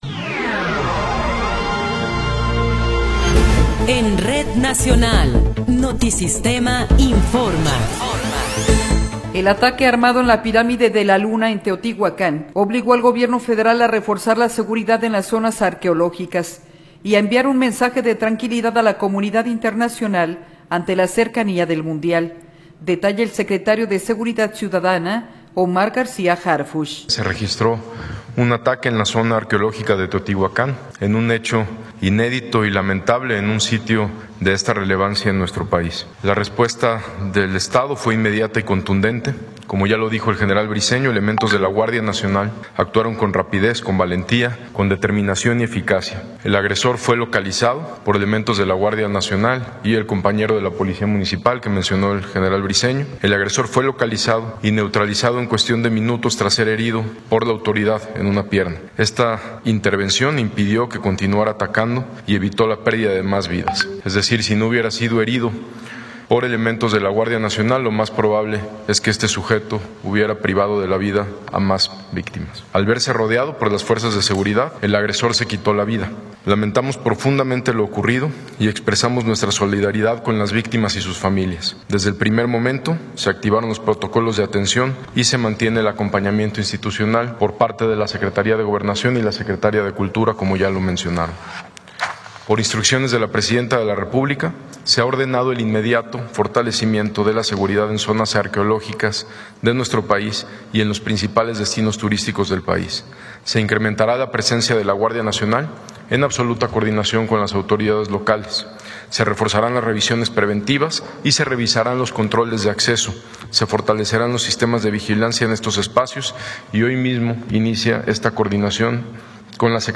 Noticiero 15 hrs. – 26 de Abril de 2026
Resumen informativo Notisistema, la mejor y más completa información cada hora en la hora.